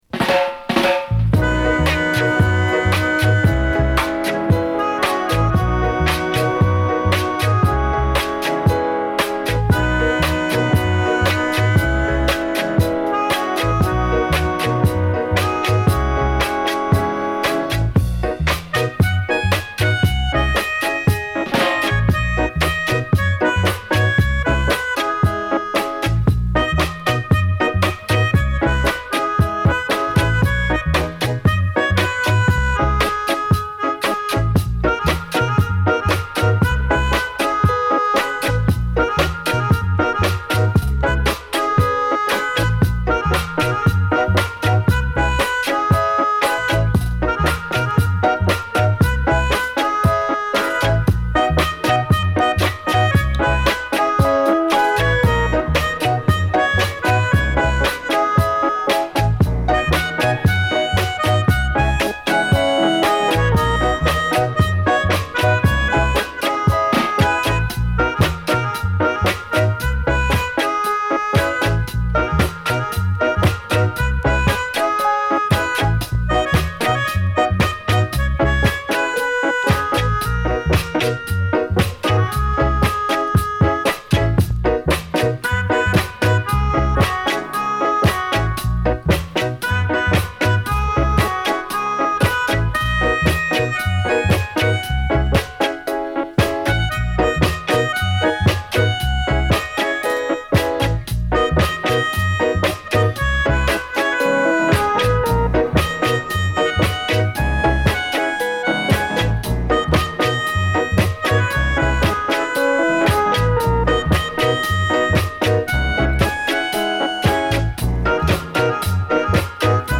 Roots Reggae